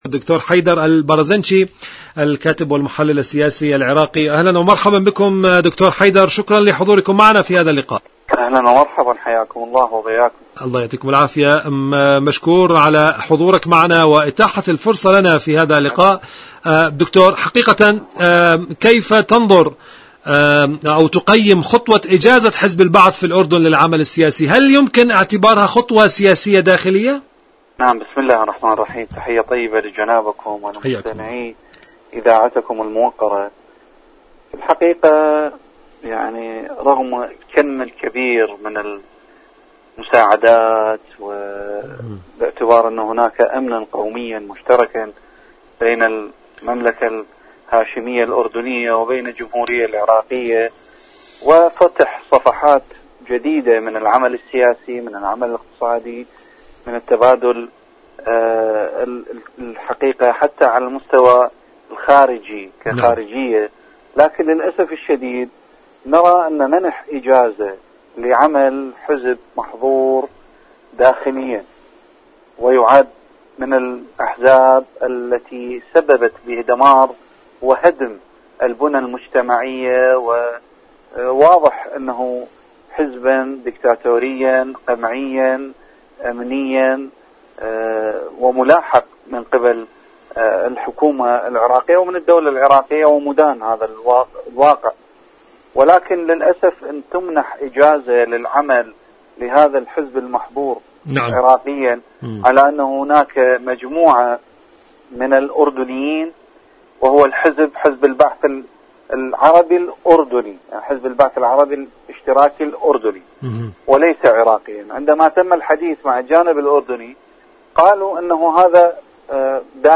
إذاعة طهران-عراق الرافدين: مقابلة إذاعية